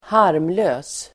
Uttal: [²h'ar:mlö:s]